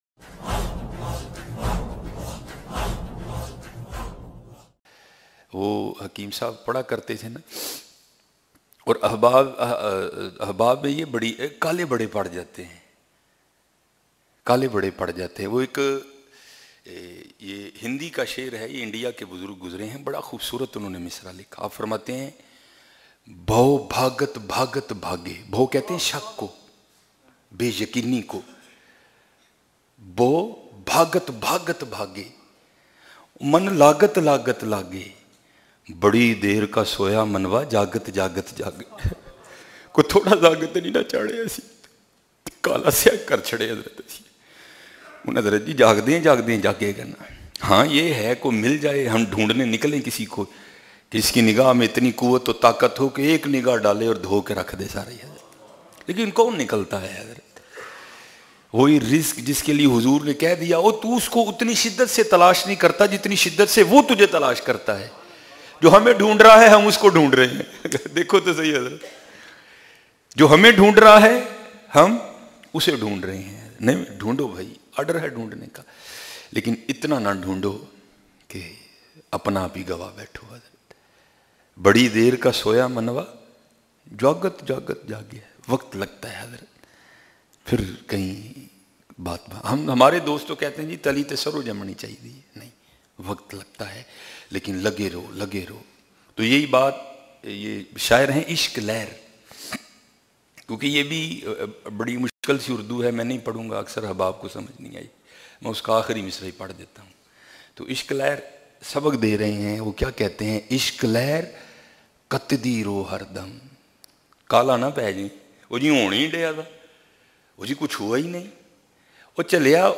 Hum Qabool Krtay hain Lattest Bayan mp3 Downlaod